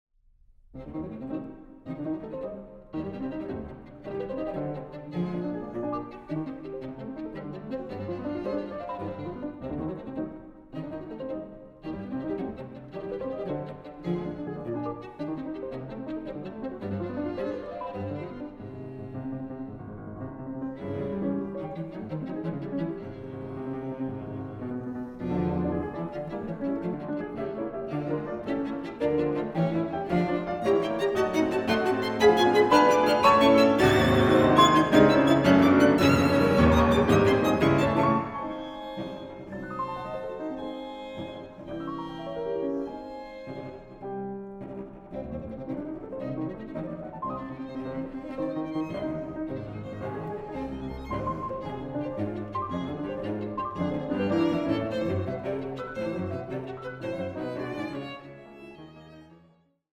Aufnahme: Festeburgkirche Frankfurt, 2024
III. Scherzo. Presto – Poco meno presto